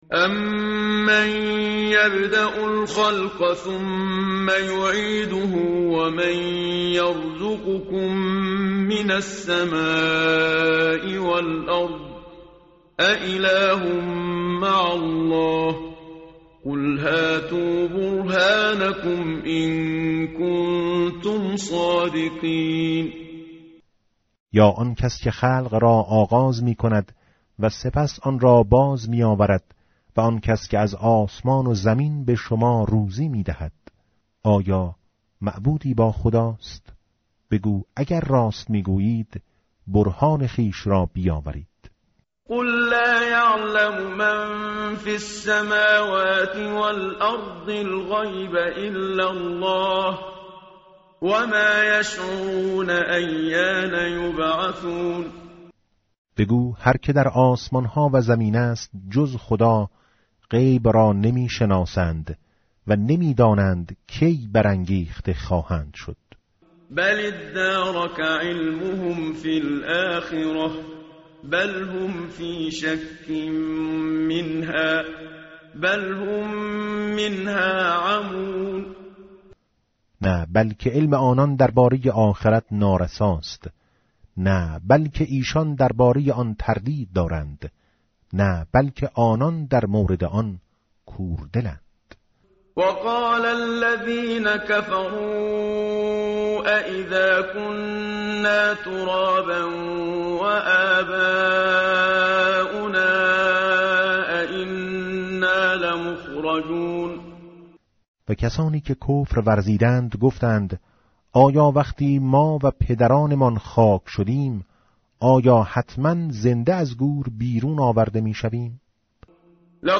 متن قرآن همراه باتلاوت قرآن و ترجمه
tartil_menshavi va tarjome_Page_383.mp3